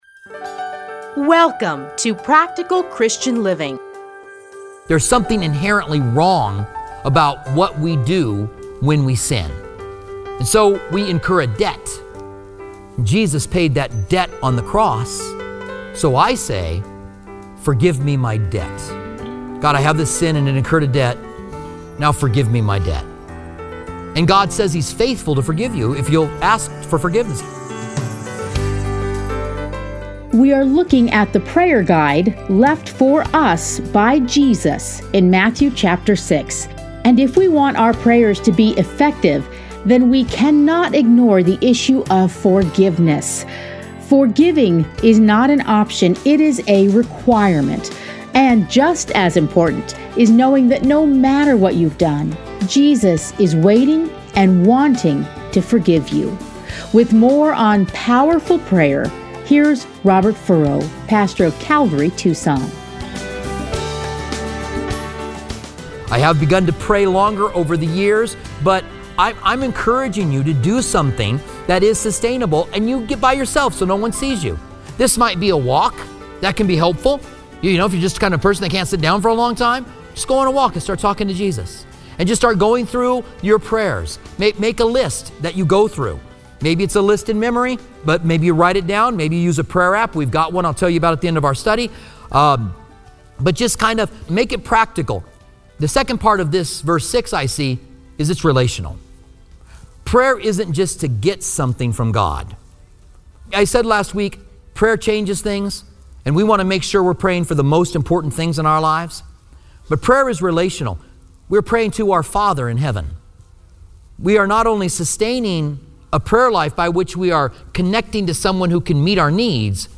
Listen here to a teaching from Matthew.